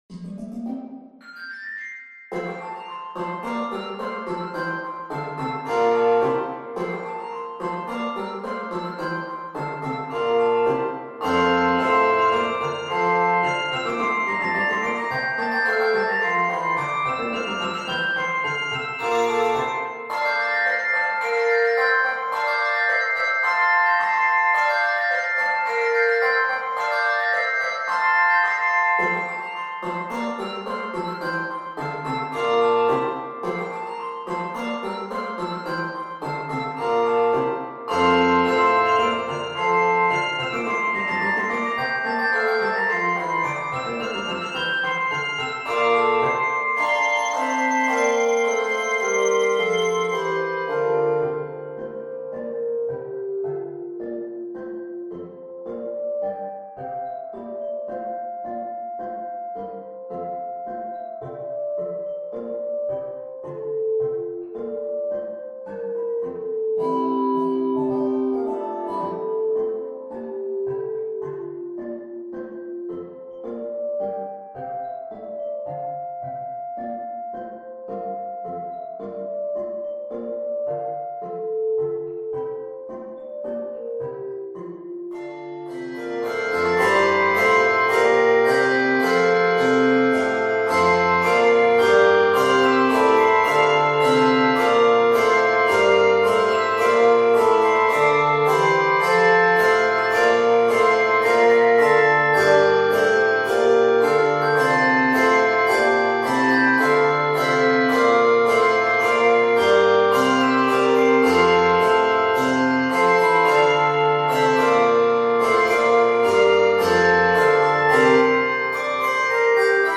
Addition of optional anvil and tambourine add to the works.